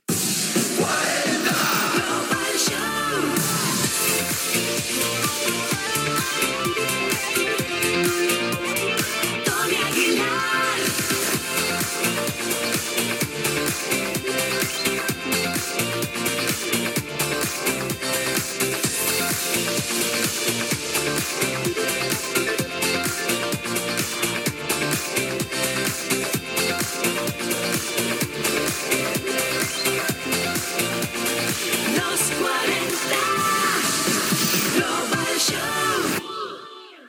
Indicatiu del programa